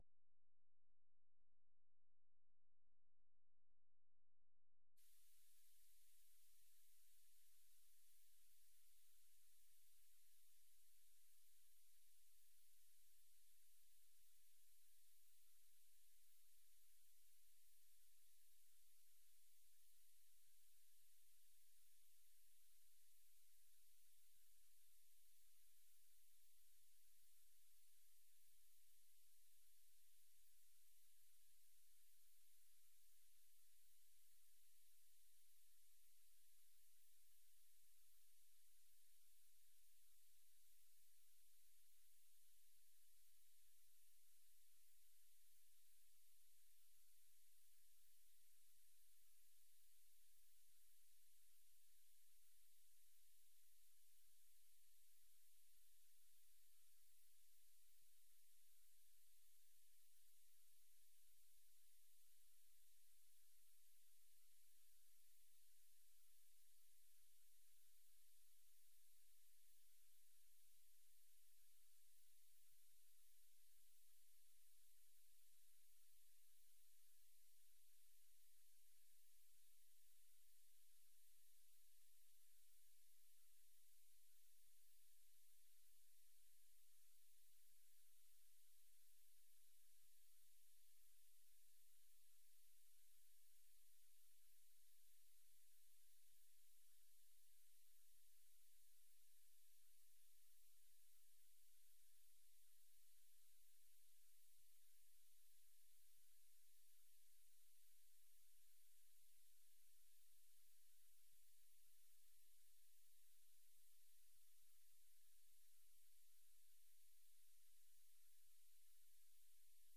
10:00 am Sunday Worship Service, we gather for worship in the heart of the city
full-service-august-21.mp3